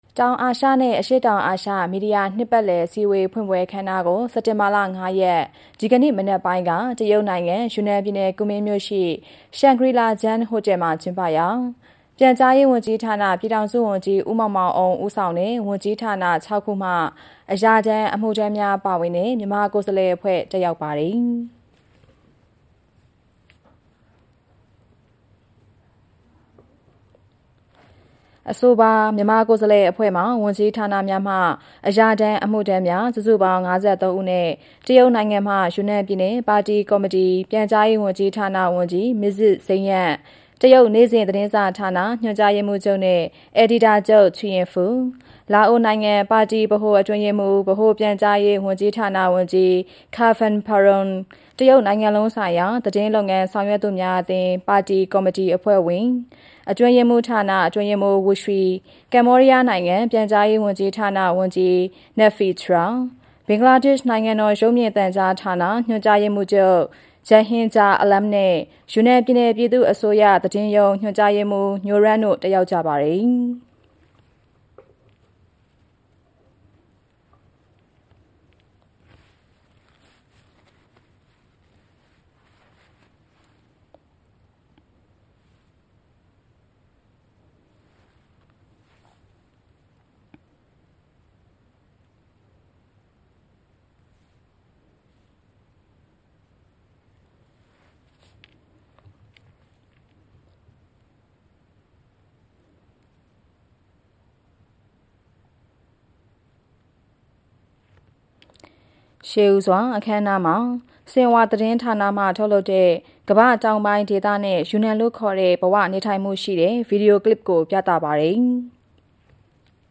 ပြန်ကြားရေးဝန်ကြီးဌာန၊ ပြည်ထောင်စုဝန်ကြီး ဦးမောင်မောင်အုန်း ဦးဆောင်သော မြန်မာကိုယ်စားလှယ်အဖွဲ့ တောင်အာရှနှင့် အရှေ့တောင်အာရှ မီဒီယာနှစ်ပတ်လည်အစည်းအဝေးဖွင့်ပွဲ အခမ်းအနားသို့ တက်ရောက်